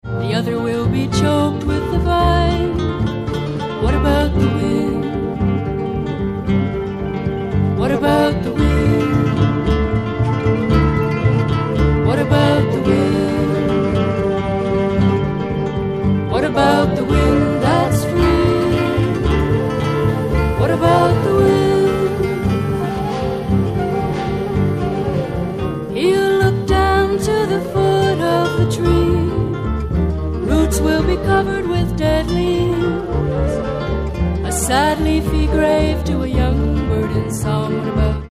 ACID FOLK/RARE GROOVE